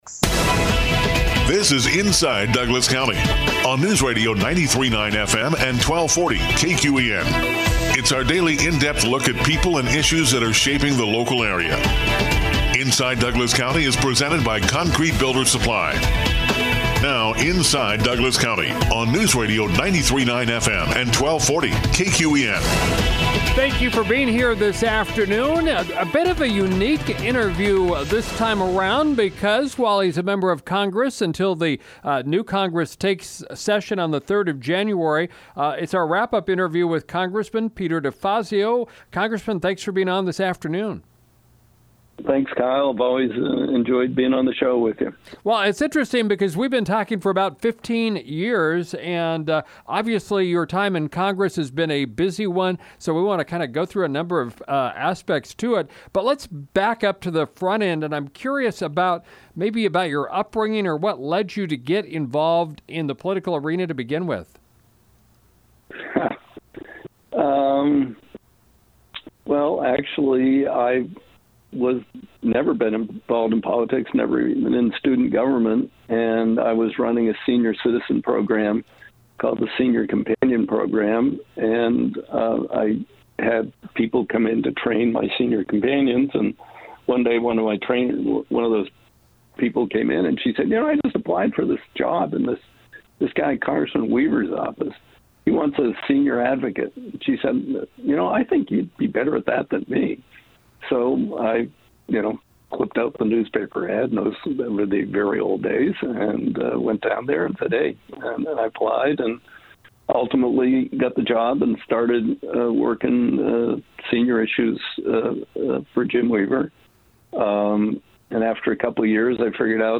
In our final interview with him before he leaves the U.S. Congress, Peter DeFazio talks about his long career and some of the highlights of being a lawmaker in Washington D.C. for 36 years.